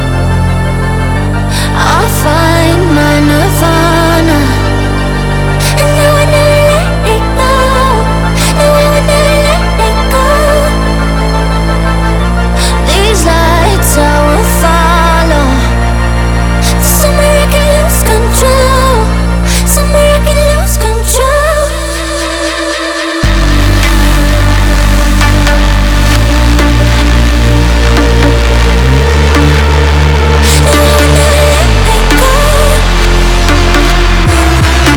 Dance Jungle Drum'n'bass
Жанр: Танцевальные